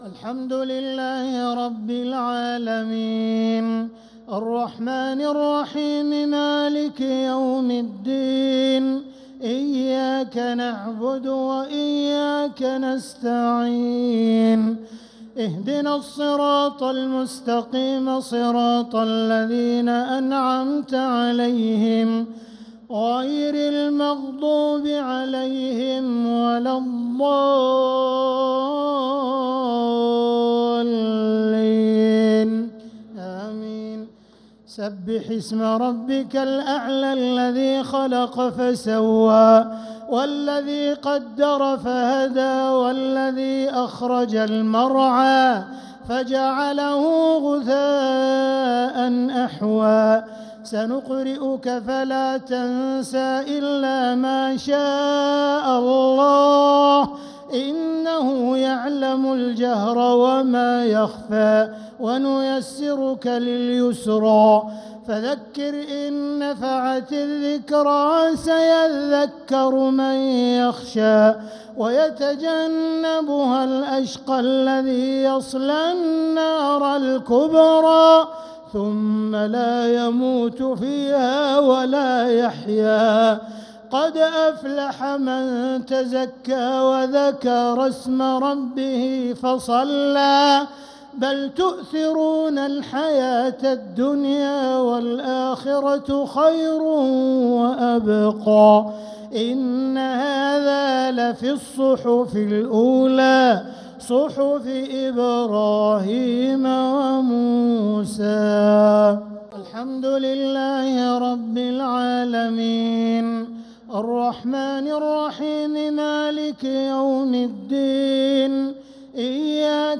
الشفع والوتر ليلة 19 رمضان 1446هـ | Witr 19th night Ramadan 1446H > تراويح الحرم المكي عام 1446 🕋 > التراويح - تلاوات الحرمين